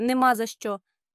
neh-MAH zah SHCHO it's nothing / don't mention it